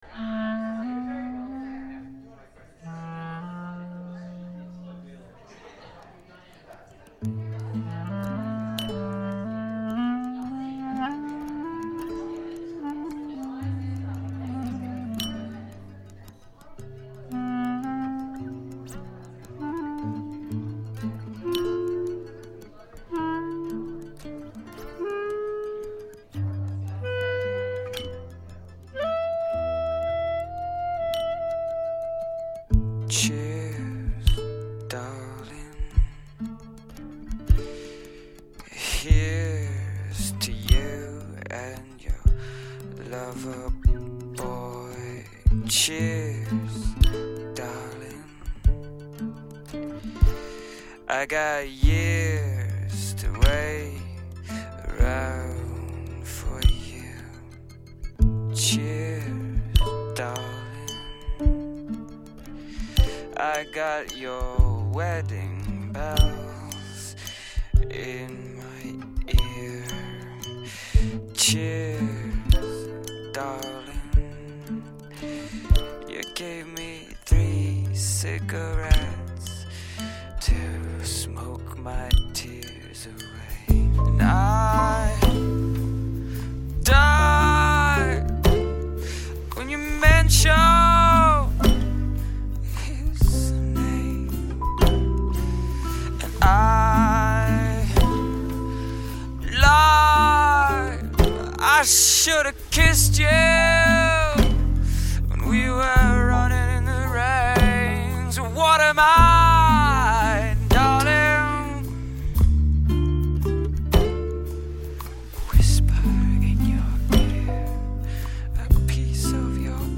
一个欲醉还醒的声音，一阵阵轻敲酒杯的背景，一场欲说还休的故事，一个人欲罢不能的撕扯